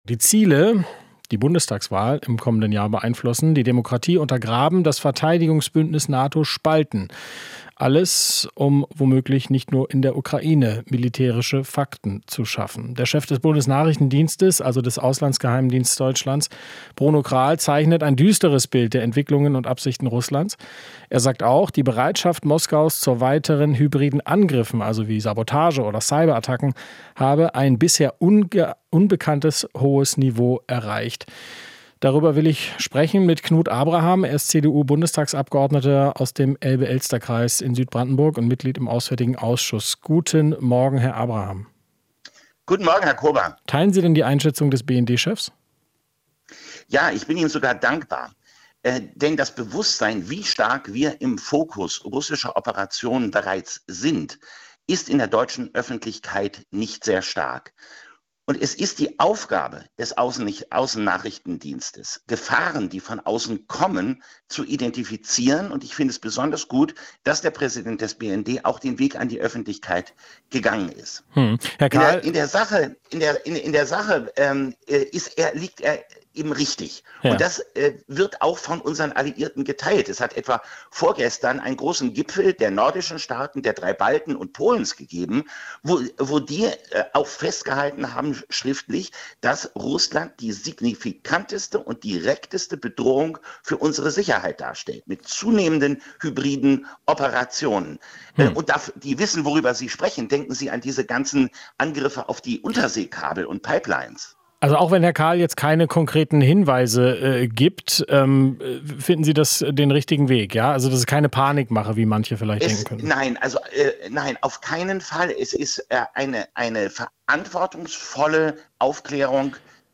Interview - Abraham (CDU): "Russland stellt direkteste Bedrohung dar"